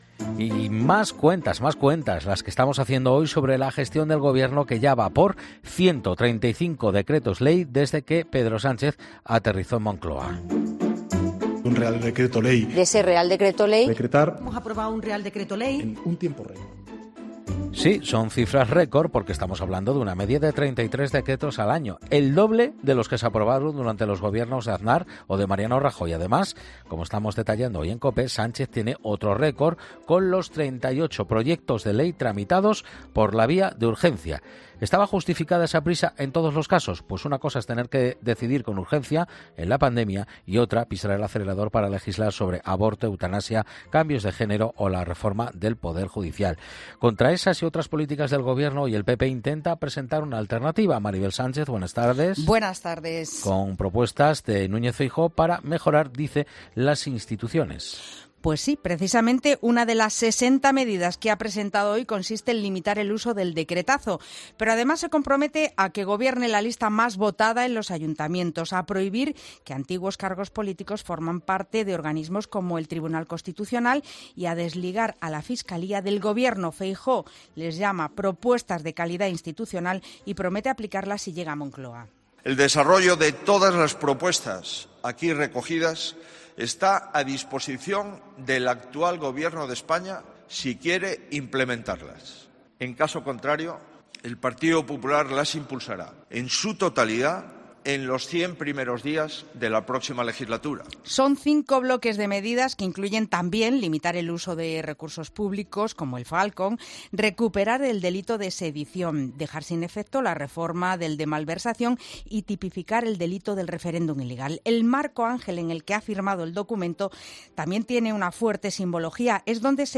Feijóo presenta su Plan de Calidad Institucional en el Oratorio de San Felipe Neri, cuna de La Pepa